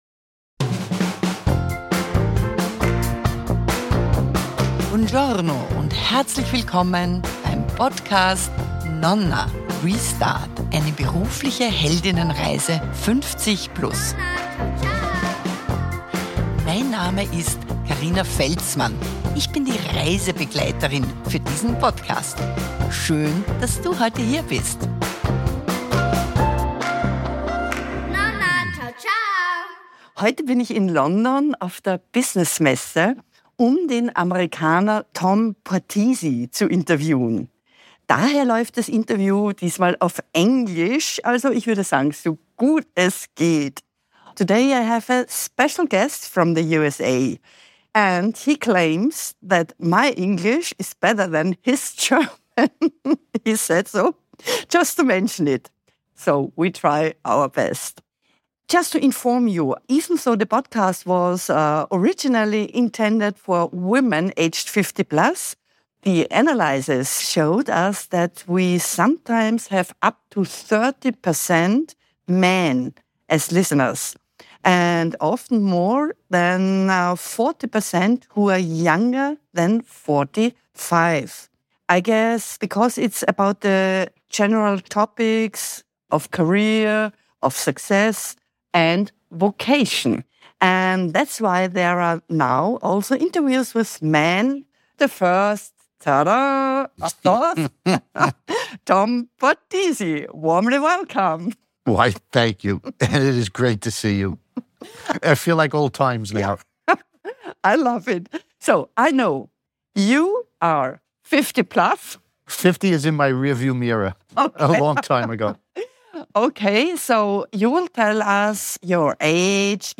The first episode, where I also interview a man...